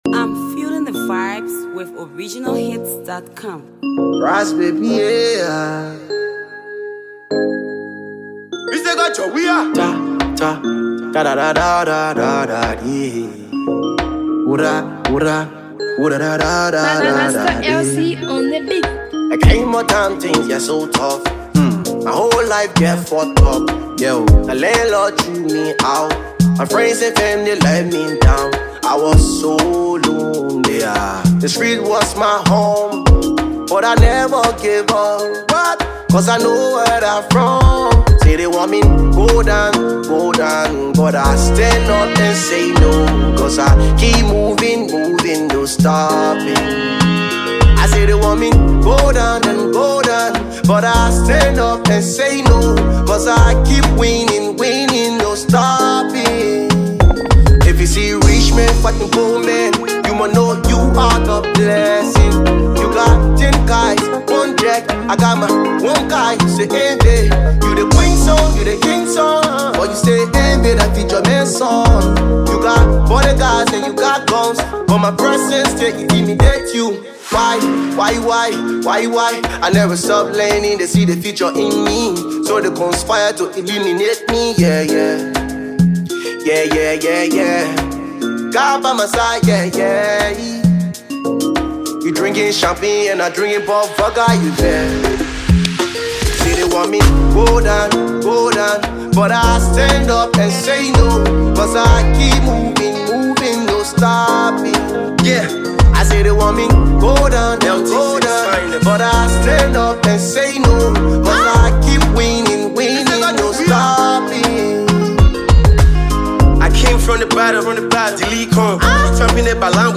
the track combines compelling lyrics with infectious rhythms
Hipco